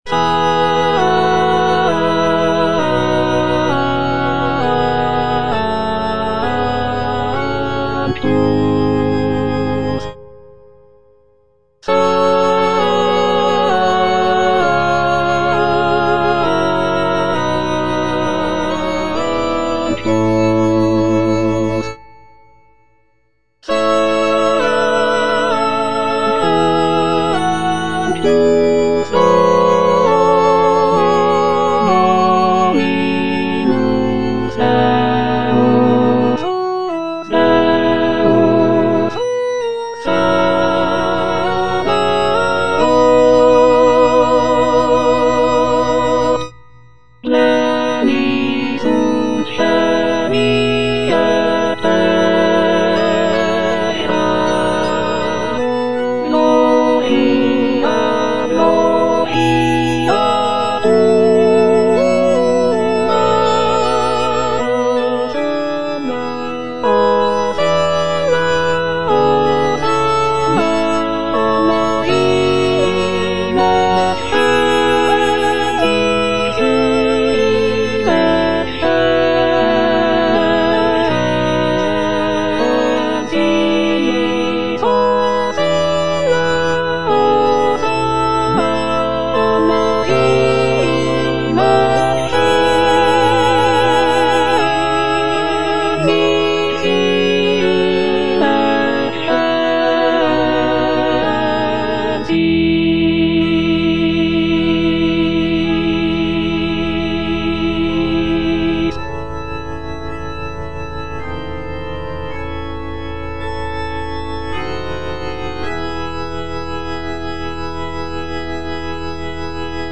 J.G. RHEINBERGER - MISSA MISERICORDIAS DOMINI OP.192 Sanctus - Alto (Emphasised voice and other voices) Ads stop: